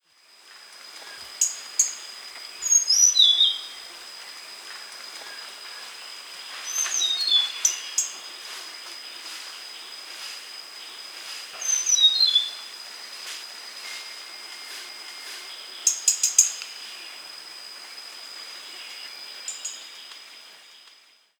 hill-blue-flycatcher-call